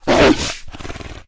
pdog_threaten_1.ogg